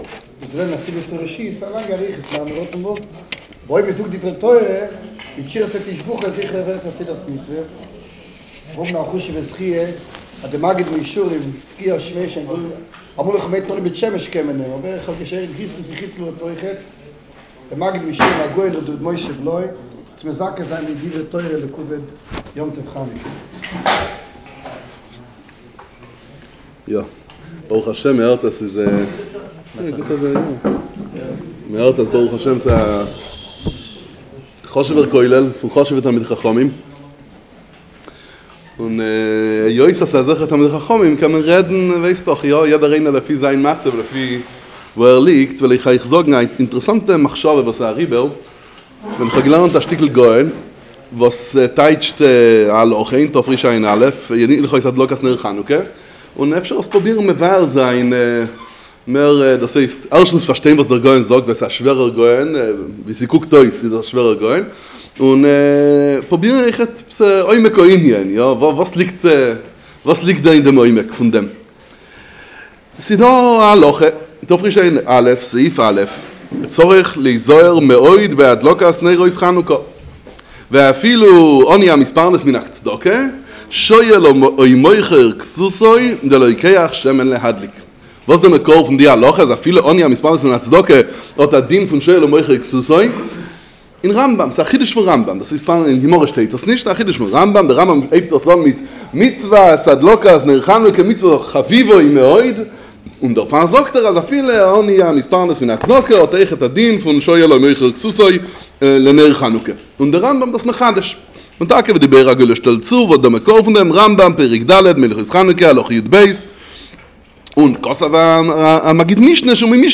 שיעור בכולל